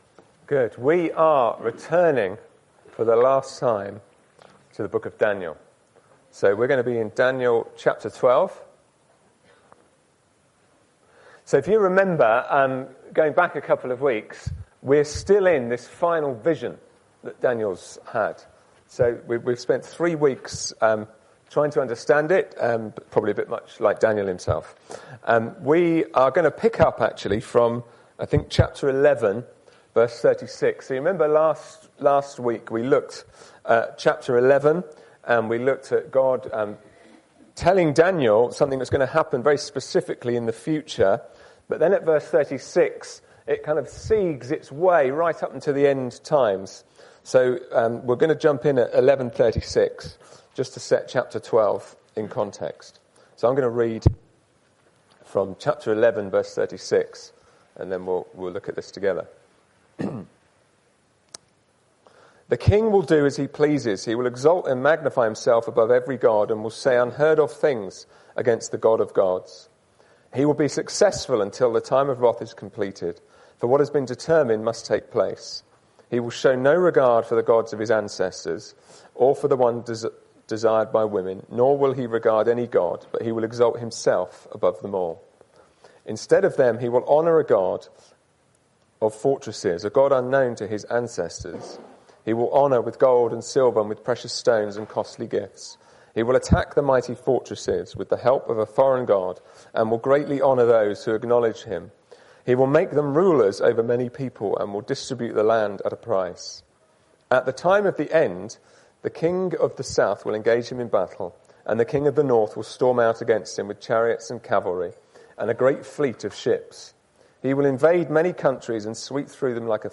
Sunday-Service-_-7th-Dec-2025.mp3